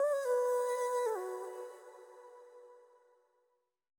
Vox [Gorgeous].wav